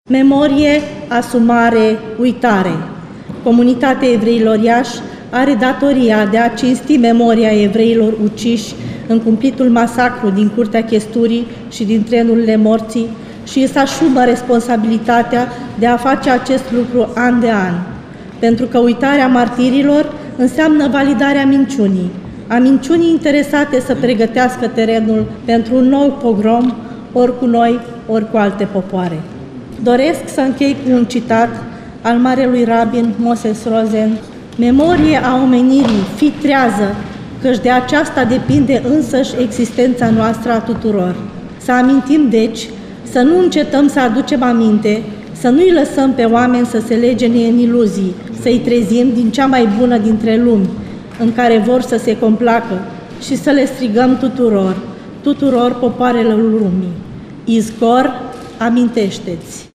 De astăzi, timp de două zile, la Iaşi, se desfăşoară acţiunile comemorative care marchează 80 de ani de la Pogromul împotriva evreilor.